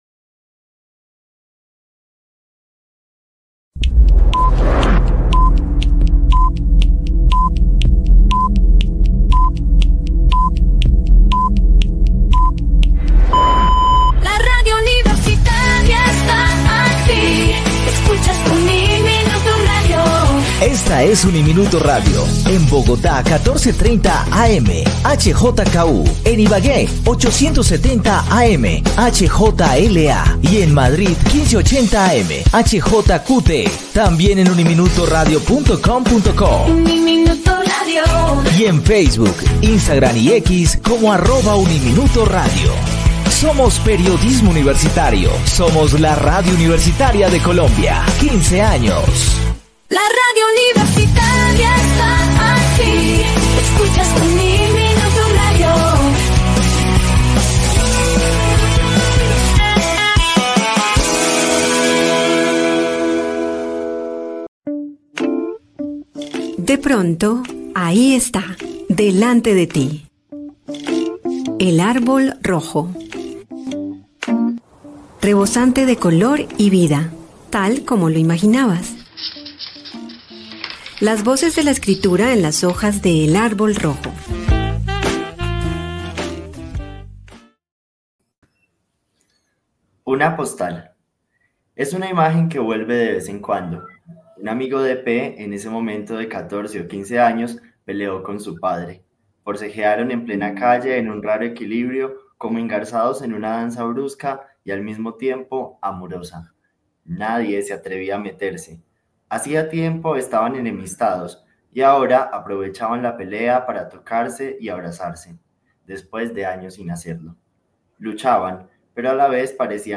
Él Árbol Rojo: conversación con el escritor y periodista